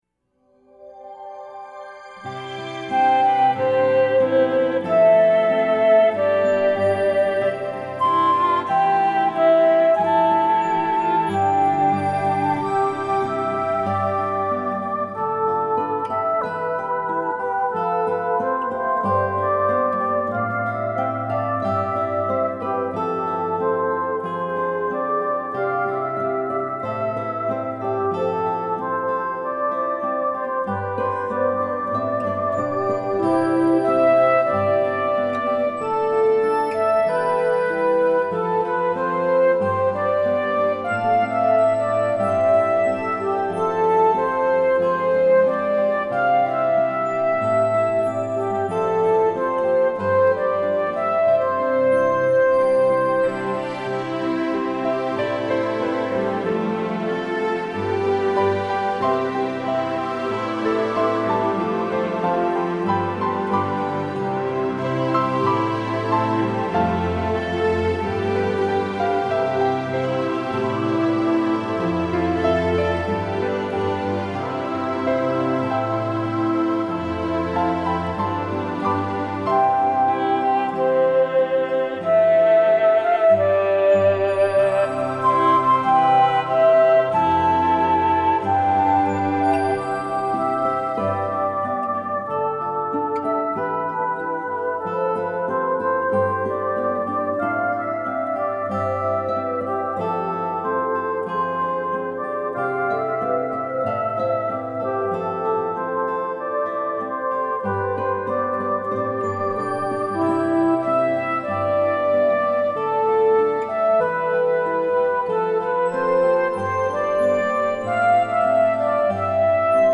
Initially, I worked with an arranger to create these lush arrangements.
6-another-you-instrumental.mp3